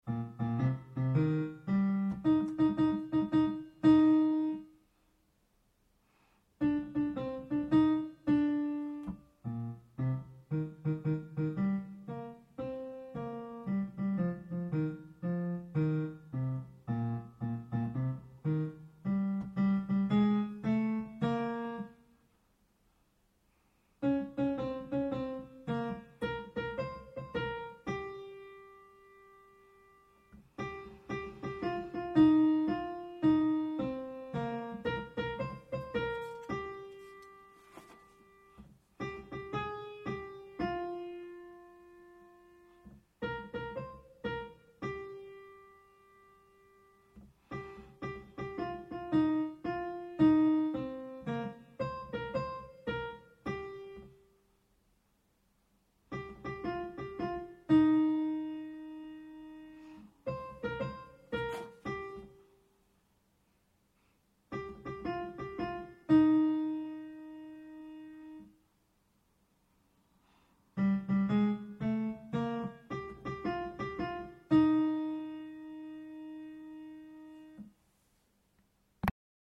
Alto (Verse 2 and Coda only)